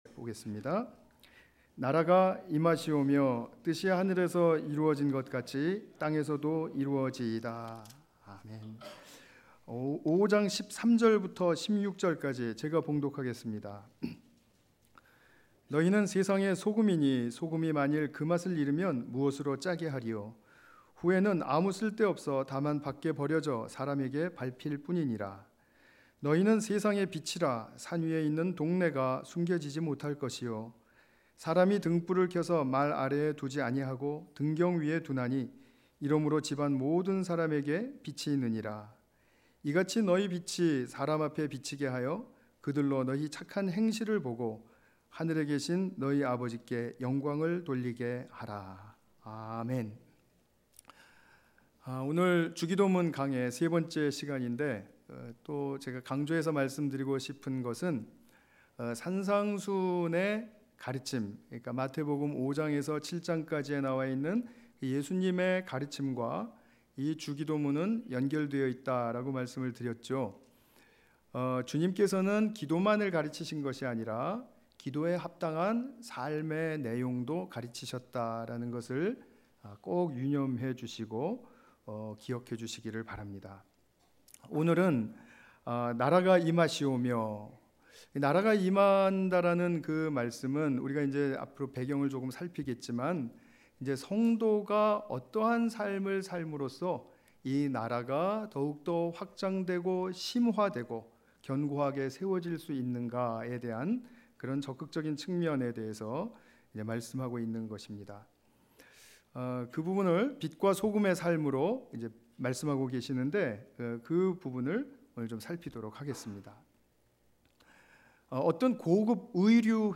5:13-16 관련 Tagged with 주일예배 Audio